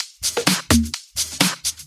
Index of /VEE/VEE Electro Loops 128 BPM
VEE Electro Loop 148.wav